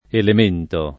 elemento [ elem % nto ] s. m.